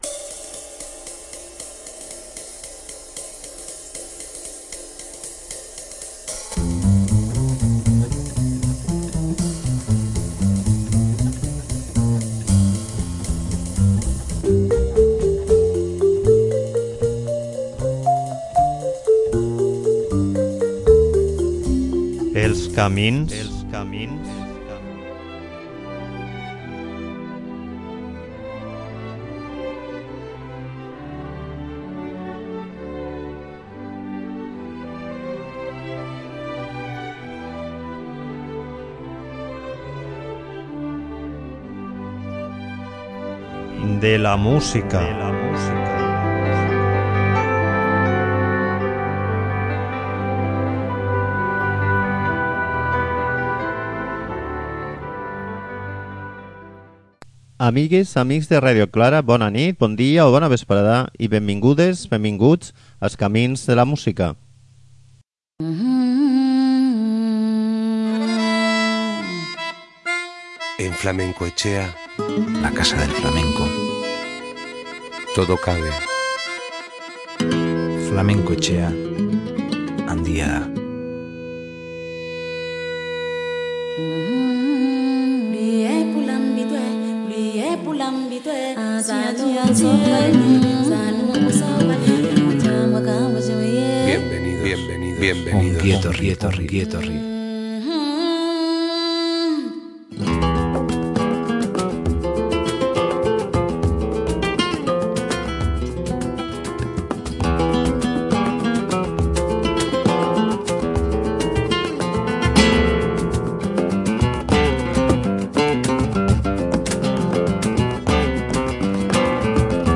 en conversa amb un dels seus protagonistes